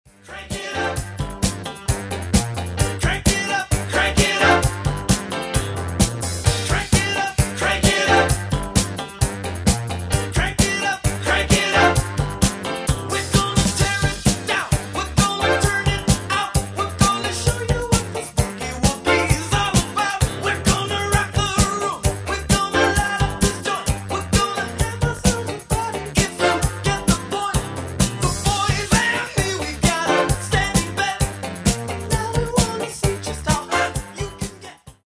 Genere:   Disco Funk
12''Mix Extended